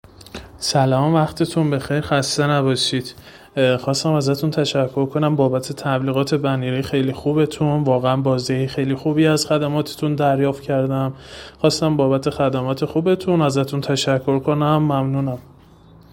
صدای مشتریان
صدای بخشی از مشتریان خوب سایت تبلیغات در گوگل